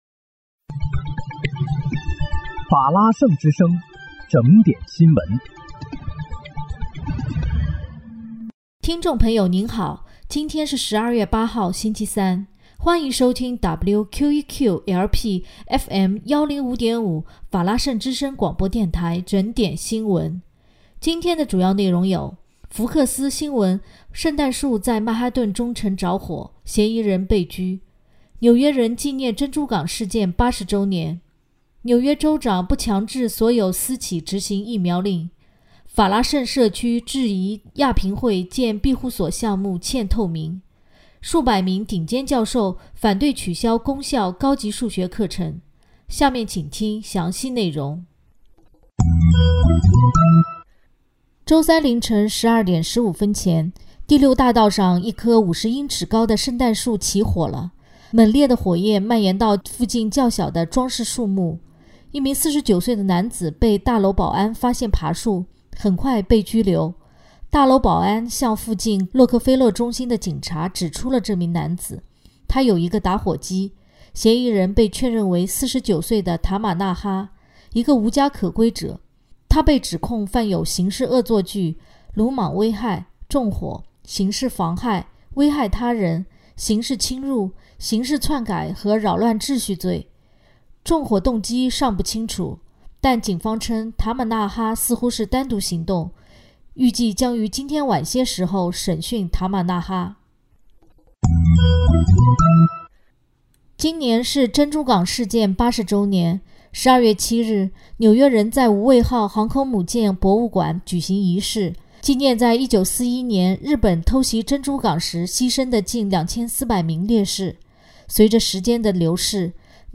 12月8日（星期三）纽约整点新闻
听众朋友您好！今天是12月8号，星期三，欢迎收听WQEQ-LP FM105.5法拉盛之声广播电台整点新闻。